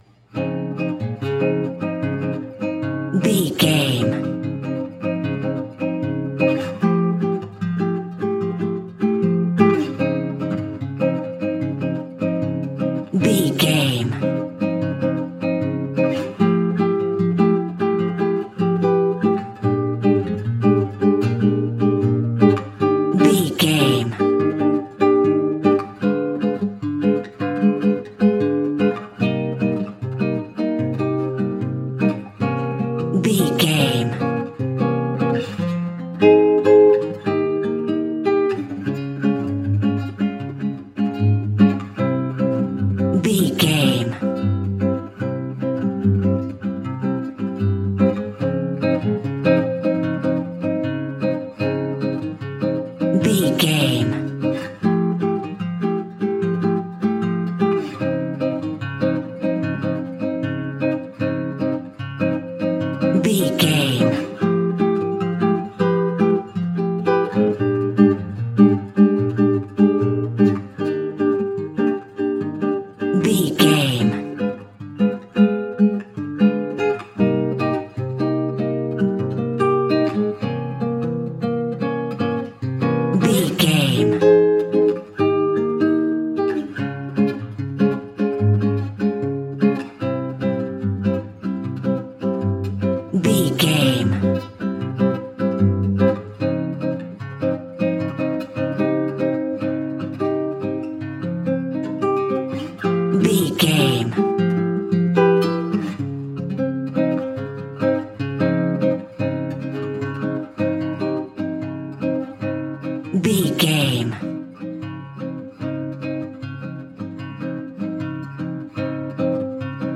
Ionian/Major
A♭
latin guitar